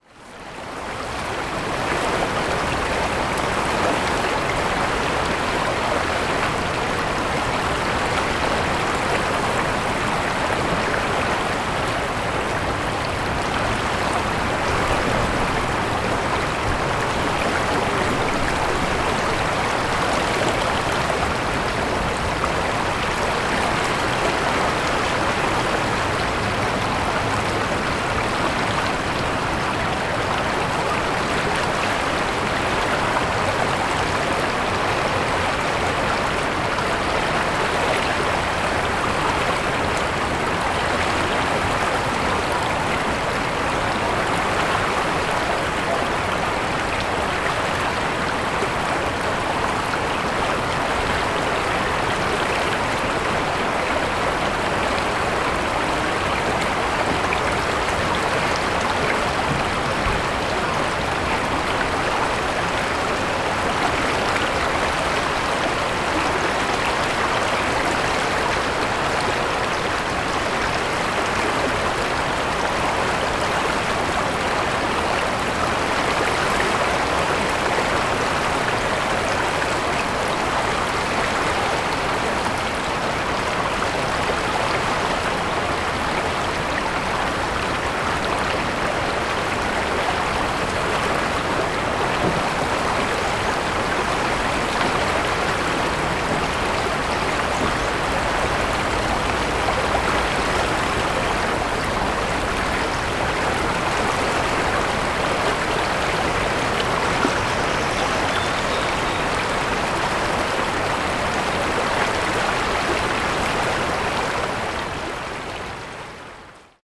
Spring trip to Yosemite Valley
018_Merced_water_sound.ogg